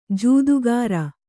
♪ jūdugāra